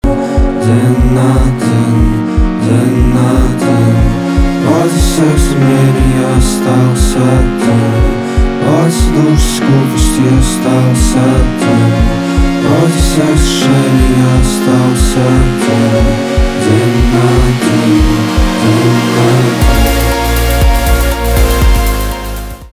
электроника
грустные , печальные , битовые , басы